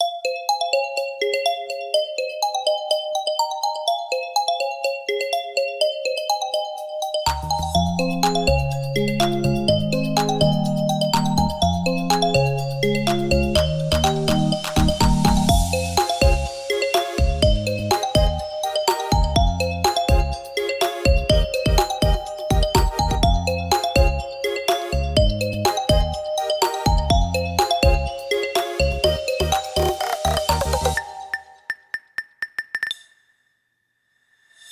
Marimba